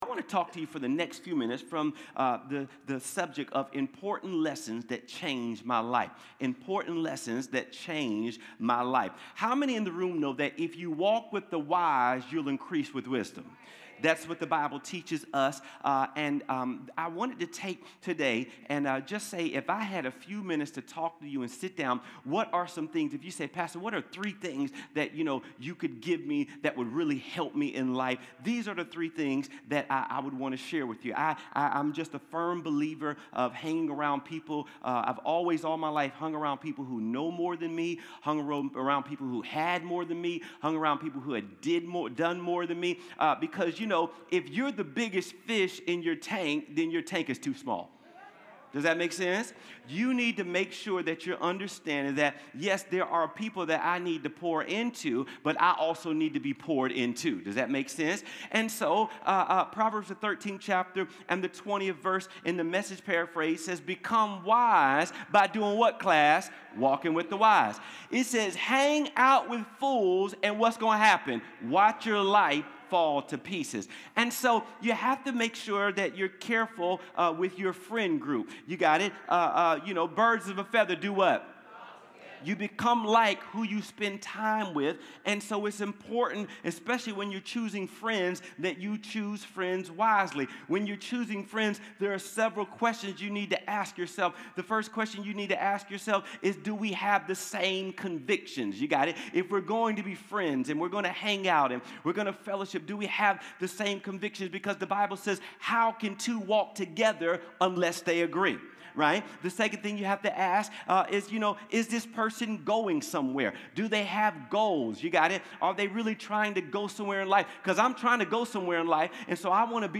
Connect Groups Events Watch Church Online Sermons Give Important Lessons That Changed My Life June 29, 2025